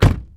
closeDoor.wav